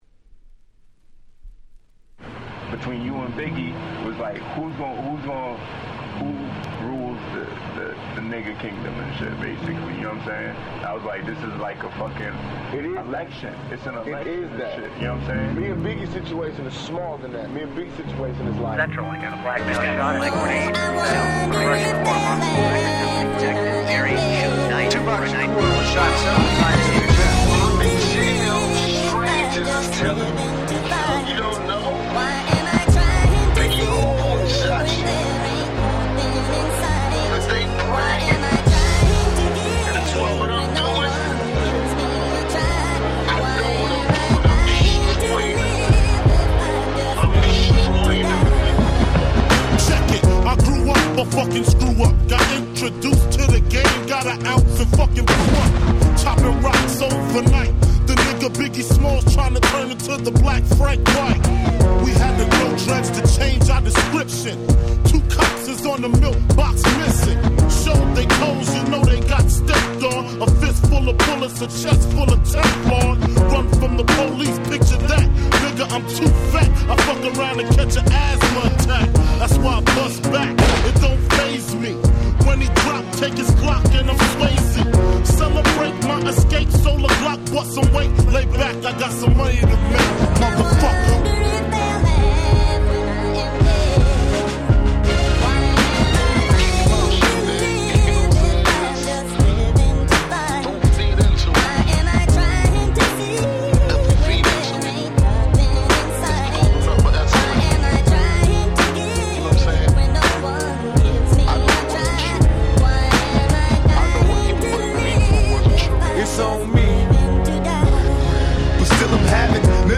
03' Smash Hit Hip Hop !!
Groovyだった原曲に対し、こちらは壮大でドラマティックなアレンジに大変身！！